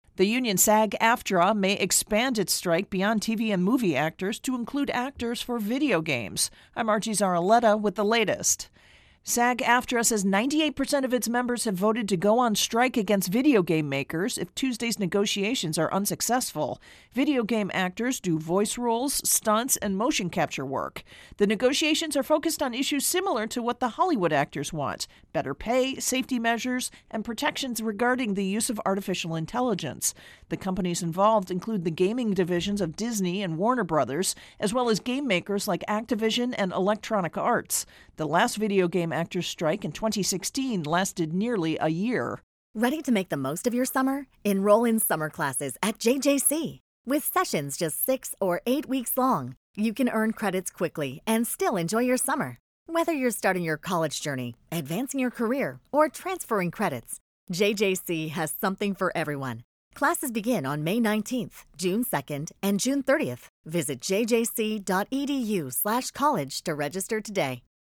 reports on Hollywood Strikes-Video Game Actors.